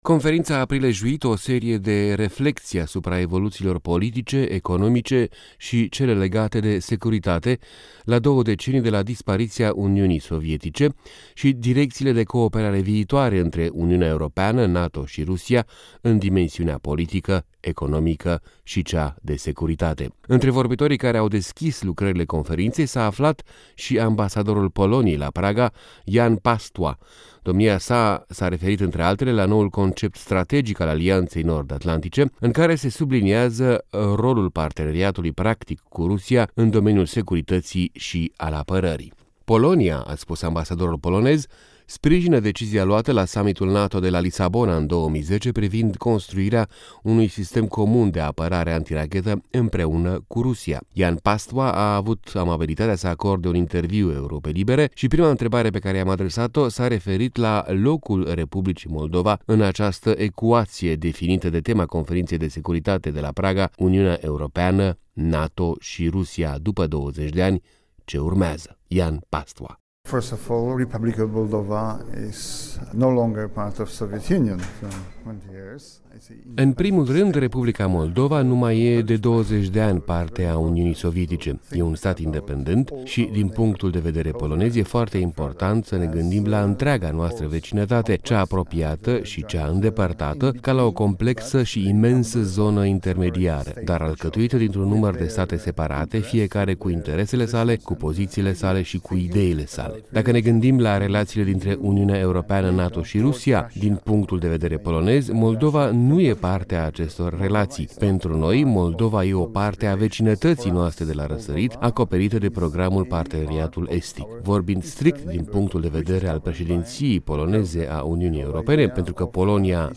Interviu cu ambasadorul Poloniei în Cehia Jan Pastwa